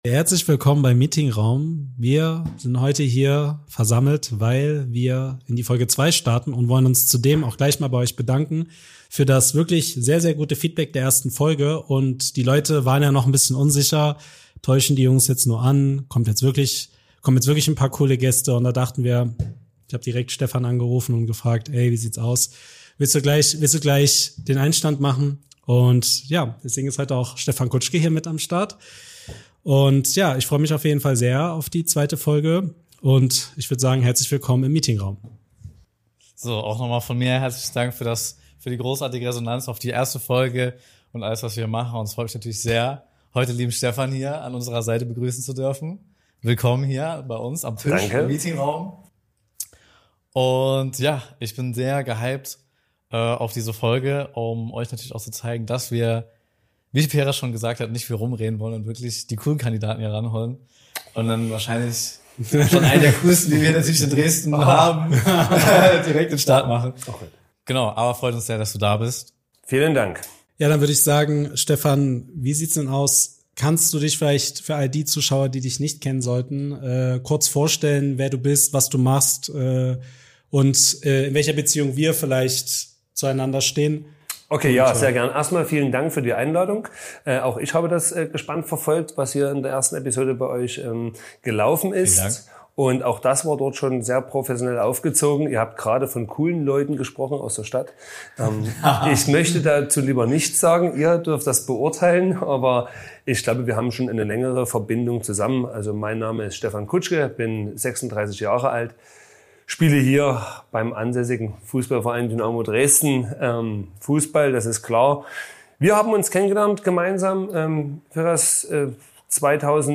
Wir starten die zweite Folge direkt mit unserem ersten Special Guest: STEFAN KUTSCHKE!